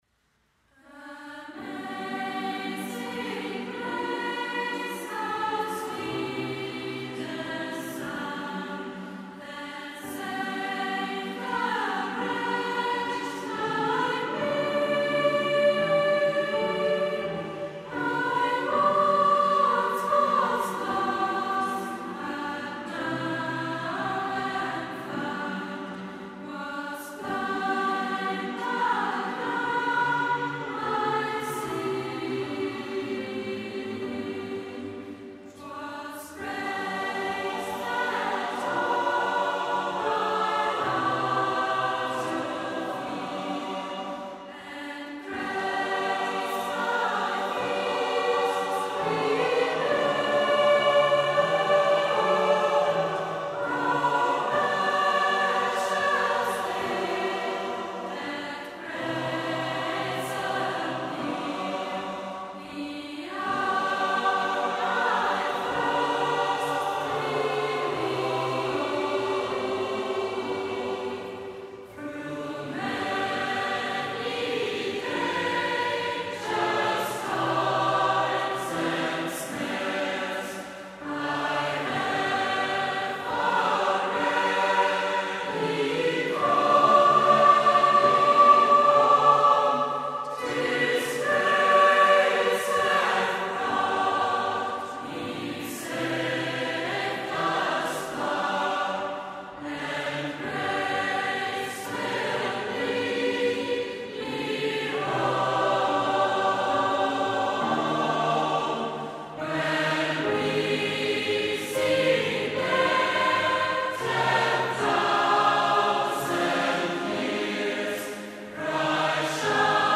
Musik-Workshop & CD-Produktion 2011
Aktuelle Geistliche Chormusik - Gospels, Spirituals